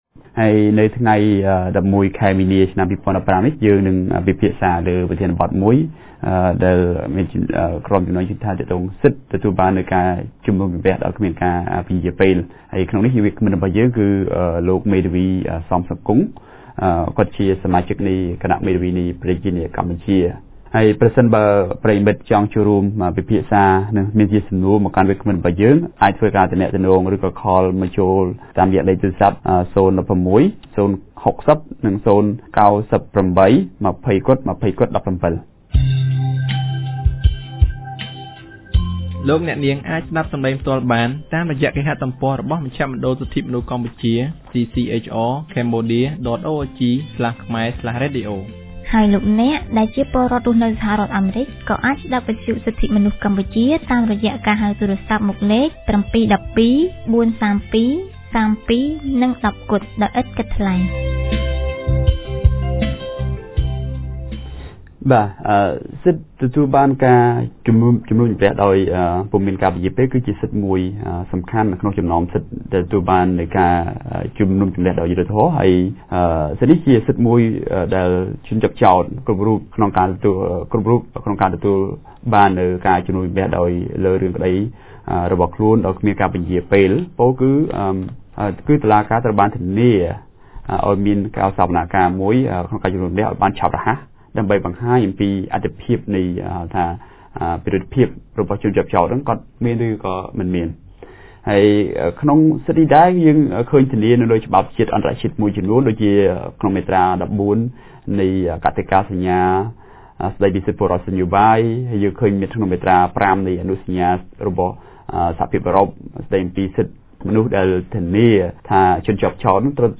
On 11 March 2015, CCHR TMP held a radio about Right to Trial without Undue Delay.